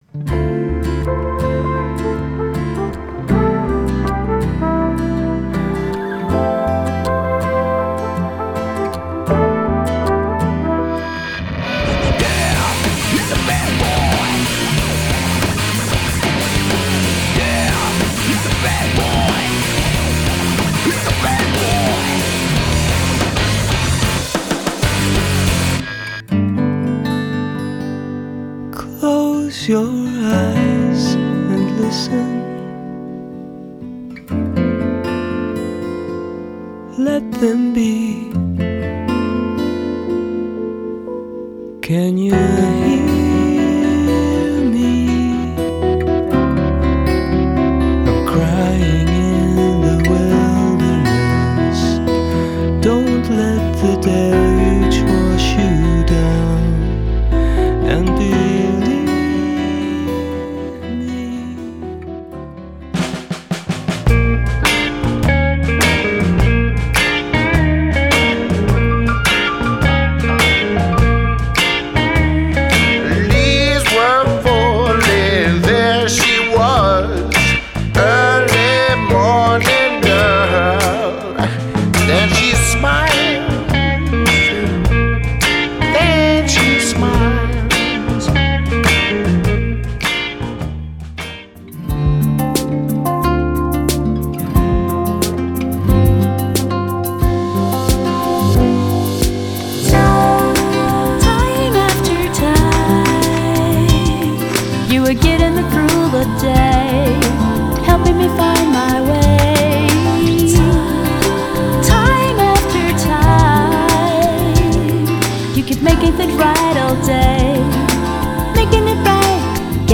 Songwriting
featuring different vocalists.